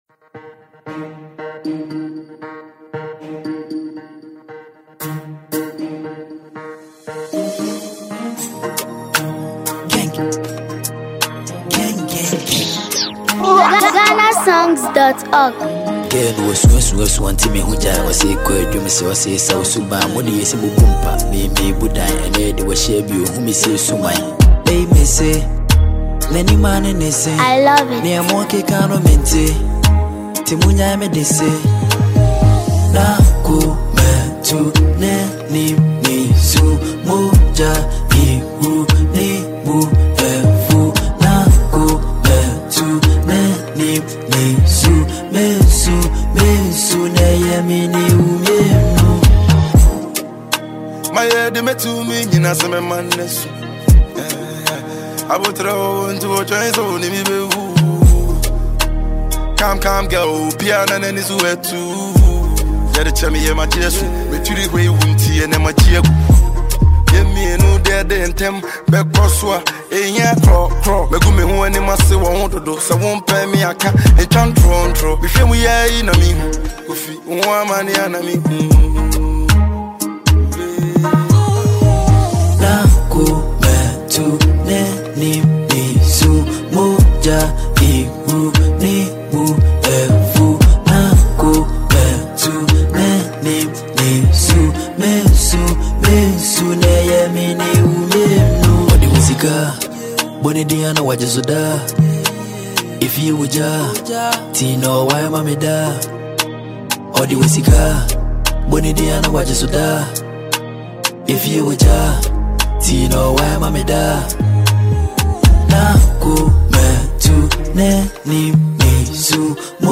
drill anthem
rugged delivery
signature deep-toned flow